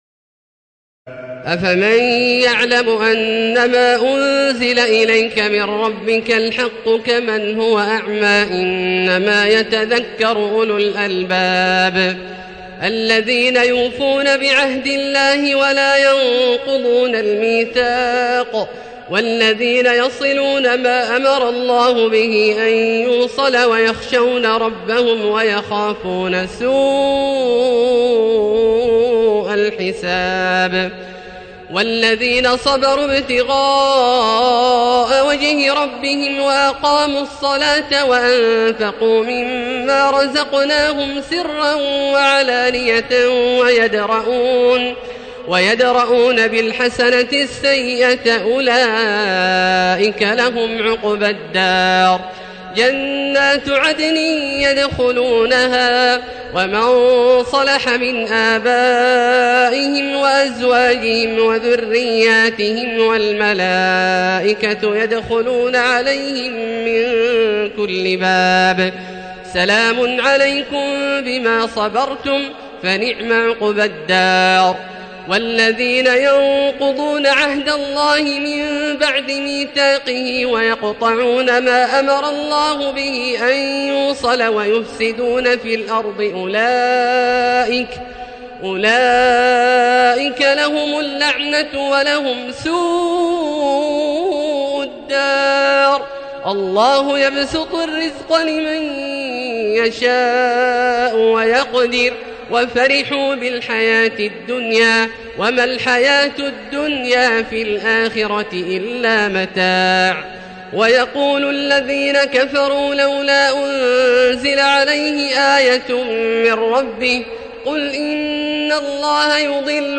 تراويح الليلة الثانية عشر رمضان 1437هـ من سورتي الرعد (19-43) و إبراهيم كاملة Taraweeh 12 st night Ramadan 1437H from Surah Ar-Ra'd and Ibrahim > تراويح الحرم المكي عام 1437 🕋 > التراويح - تلاوات الحرمين